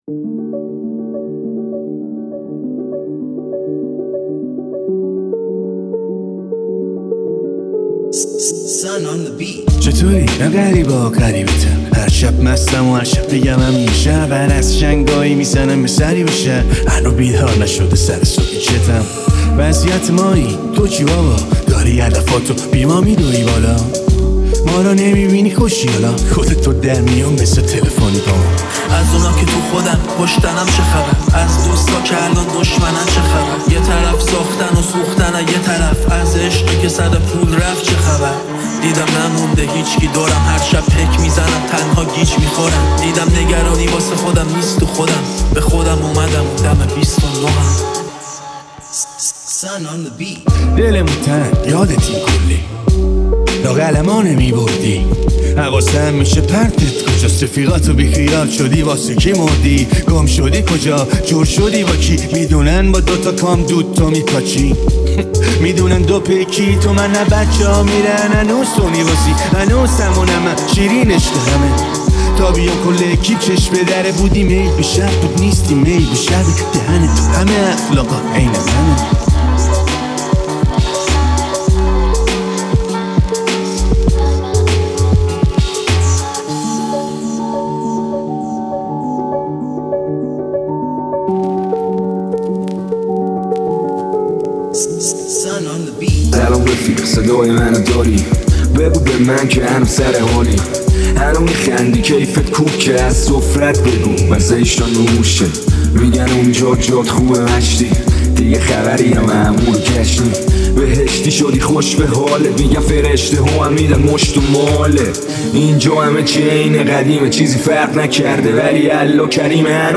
ریمیکس شاد